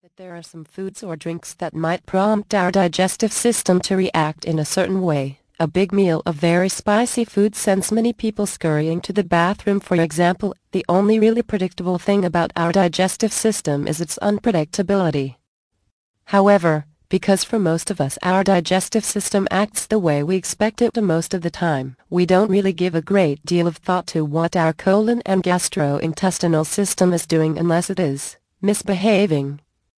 Dealing with Irritable Bowel Syndrome naturally audio book